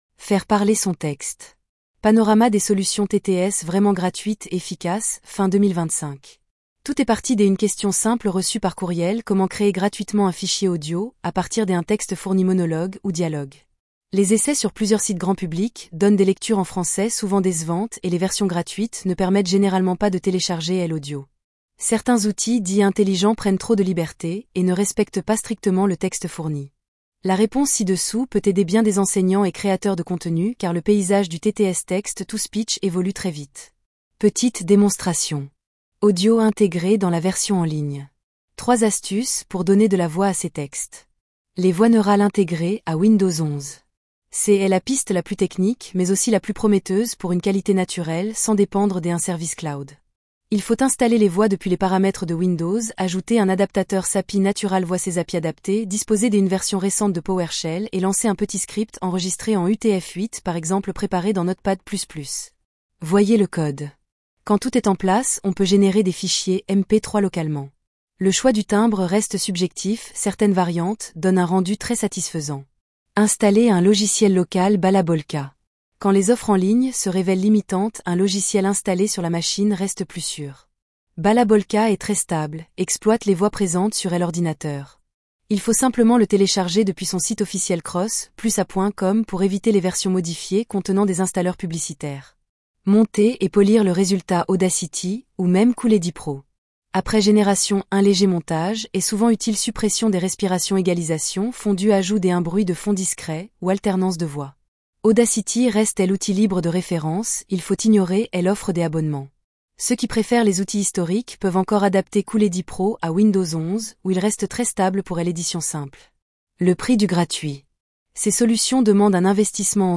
Lecture de l’article entier (brouillon réalisé en quelques secondes) :
5-fr-fr-vivienneneural-2.mp3